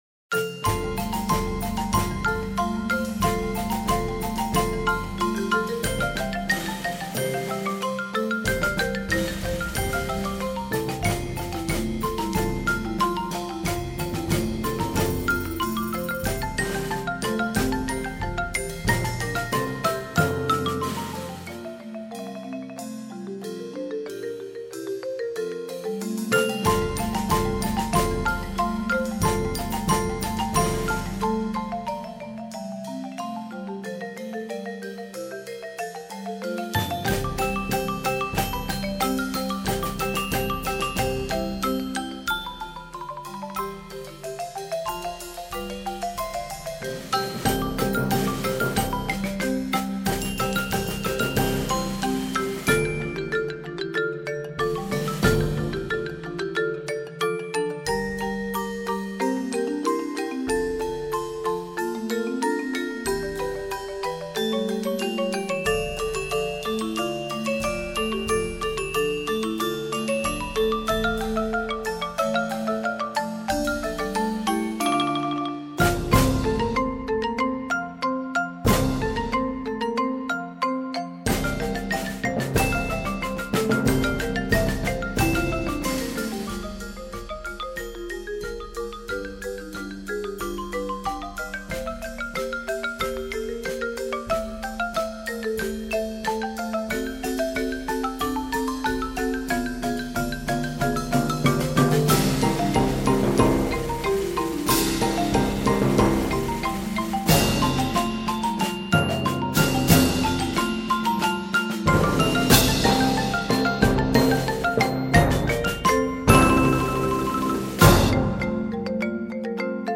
Voicing: Percussion Choir